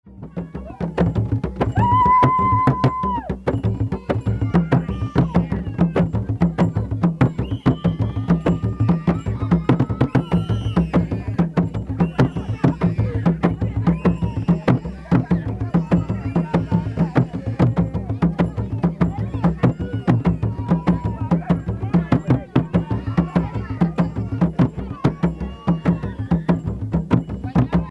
circonstance : funérailles
Pièce musicale éditée